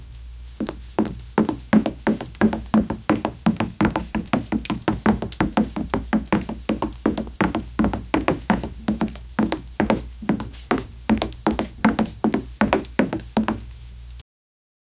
Quick whoosh as a sword is swung. 0:08 Created Sep 5, 2024 7:39 PM Very Quick Running footsteps, wood ground. 0:15 Created Nov 25, 2024 11:48 PM Quick record scratch during a DJ set. 0:08 Created Sep 5, 2024 7:41 PM Il cuore fa un battito.
very-quick-running-footst-r73wrj4m.wav